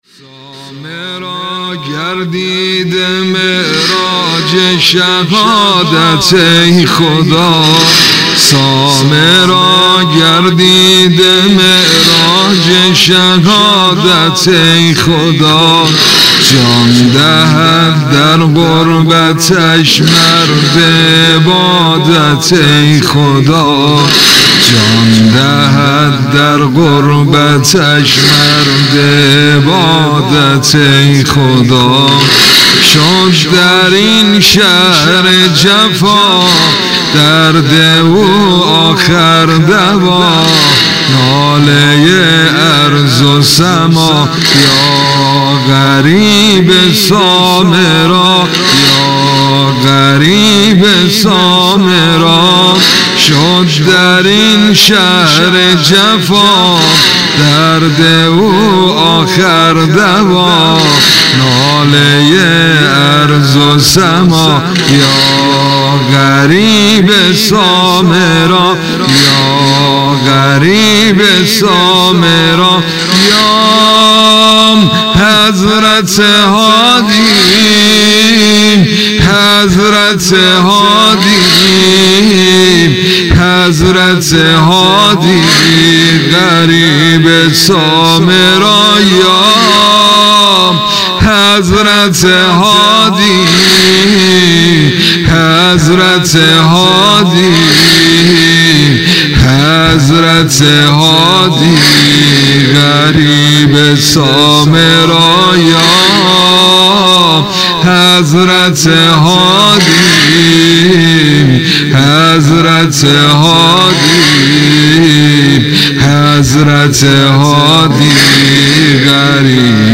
سبک ۱ ـ ذکر / زمینه سنگین